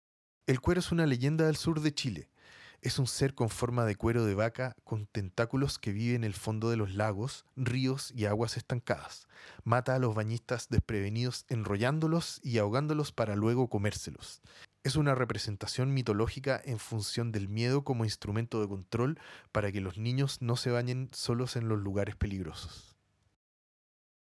Audioguía